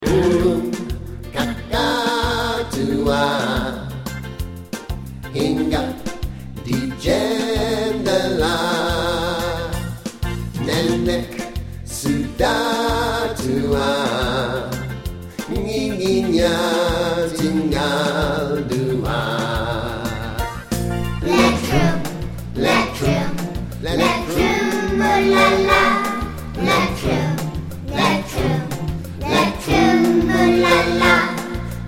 Indonesian Folk Song